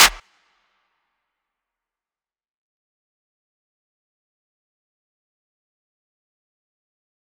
DMV3_Clap 4.wav